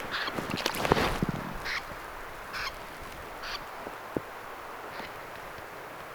tuollaisia kurppia oli
tuollaisia_kurppia_oli_1.mp3